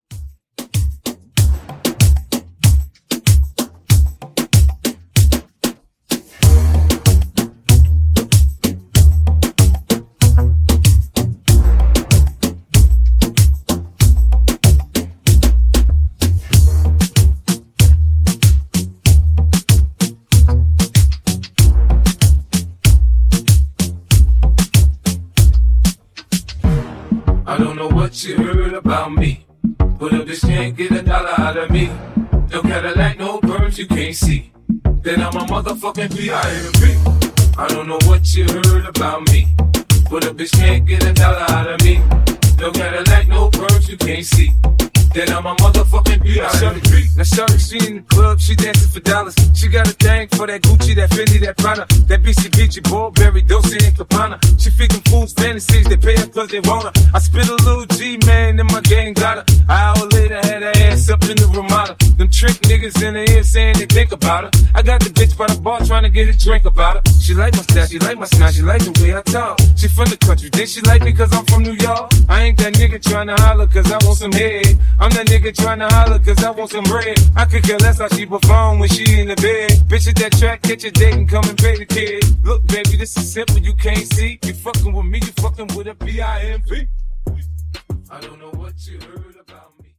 Genre: HIPHOP
Dirty BPM: 95 Time